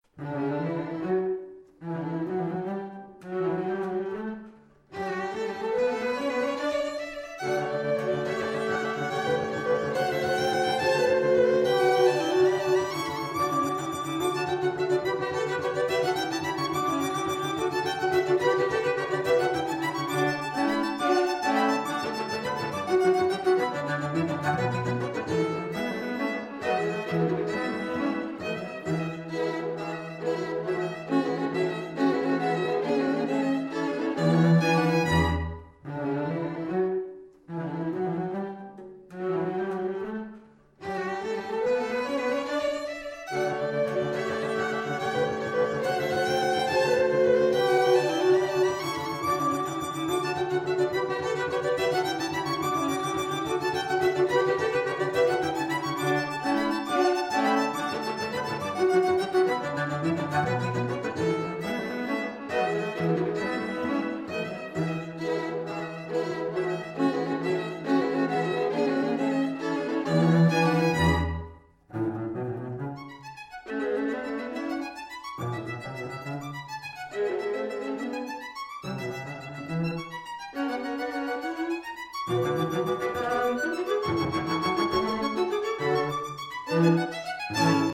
Soundbite 4th Movt